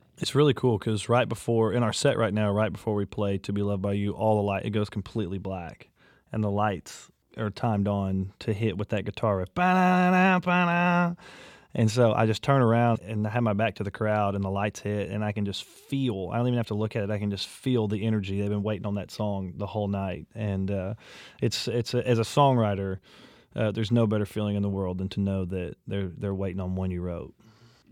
Audio / Parker McCollum talks about the energy from the crowd right before he performs "To Be Loved By You."